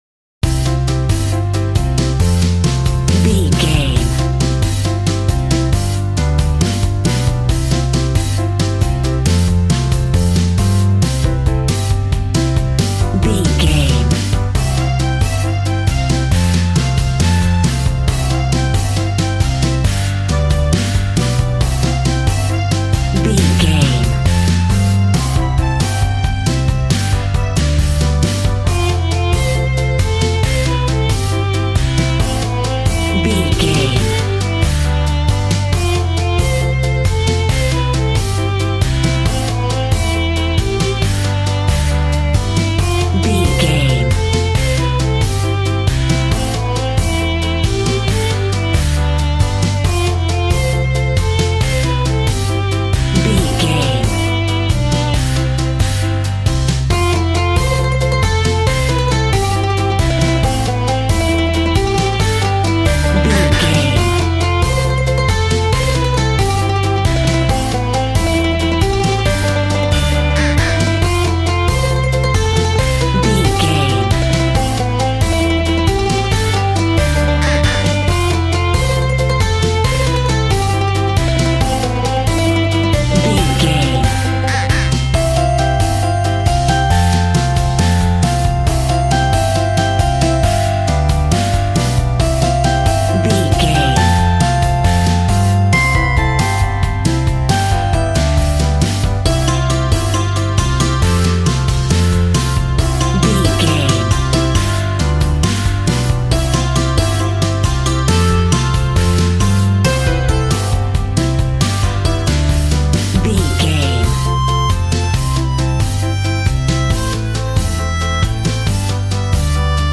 Ionian/Major
cheerful/happy
bouncy
electric piano
electric guitar
drum machine